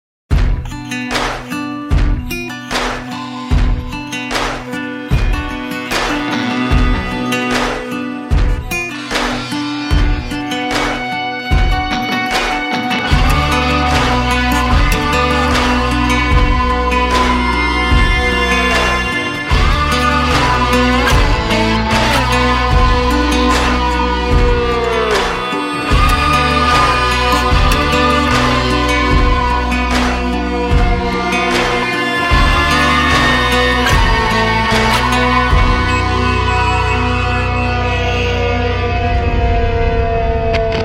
саундтреки
без слов
alternative